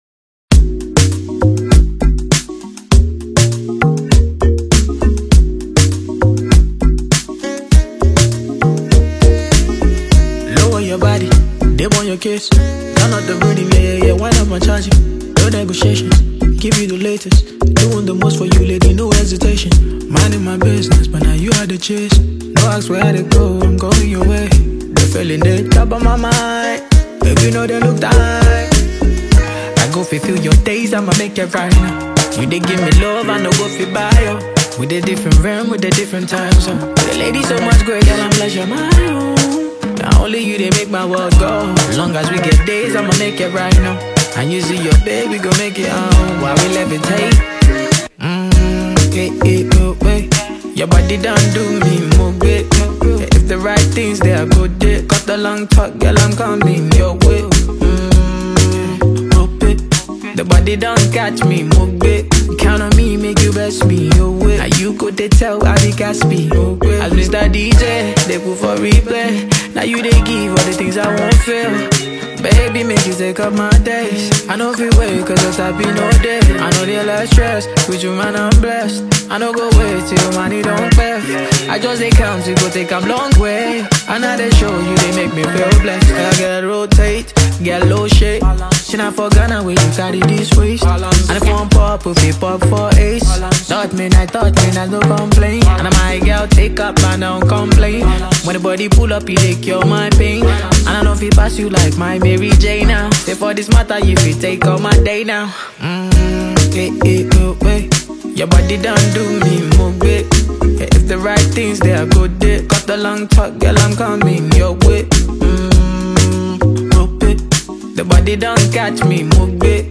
Nigerian R&B, Afropop, Afrobeats Label